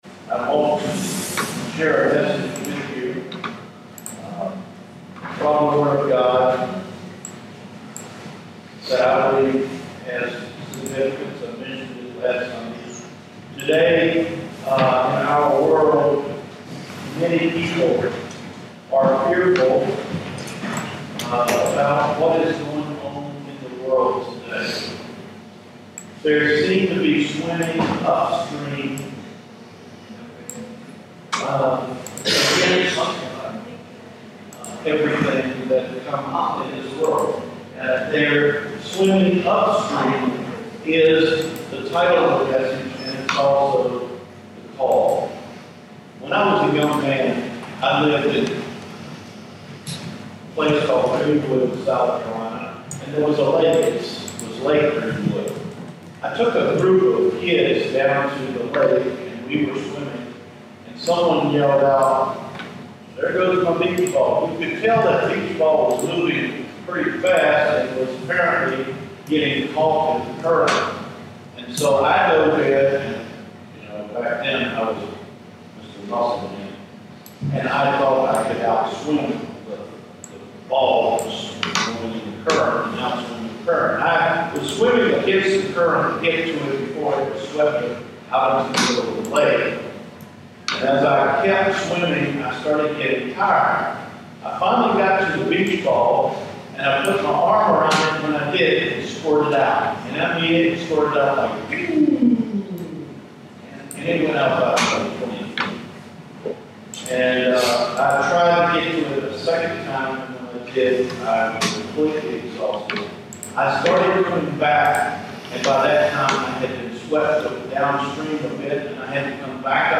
Mirroring Culture-October 22 Recorded Sermon – Cedar Fork Baptist Church